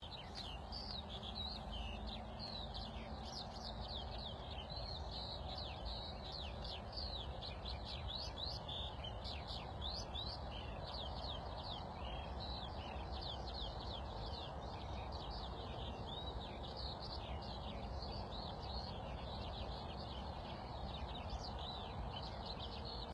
Im einsetzenden Morgengrauen startet urplötzlich der Gesang der Vögel auf den Feldern: zuerst ist eine einzige Feldlerche zu hören, und kurz danach singen alle Feldlerchen gemeinsam - als hätten sie schon auf der Lauer gelegen und nur auf das Startsignal gewartet. Und wenige Minuten später wird es wieder still.
Den Gesang der Feldlerchen habe ich mit den Handy über die BirdNET-App aufgezeichnet - auf das Screenshot klicken/tippen, um es anzuhören: